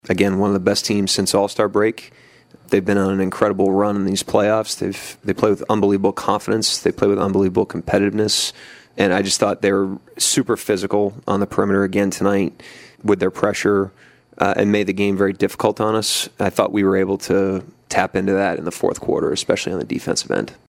Thunder head coach Mark Daigneault says the Pacers have been on a good run.